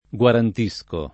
gUarant&Sko], ‑sci